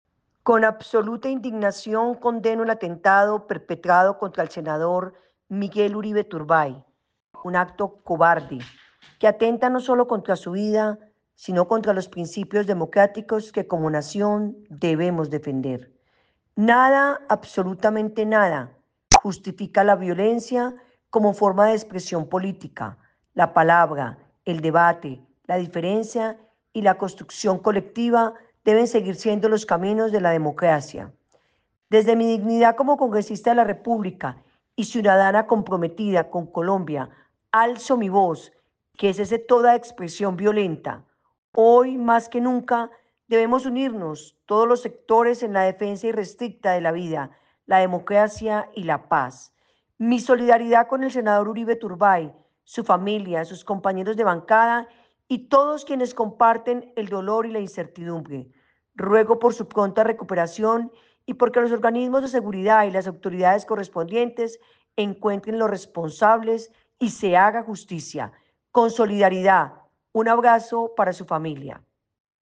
Congresista Sandra Aristizábal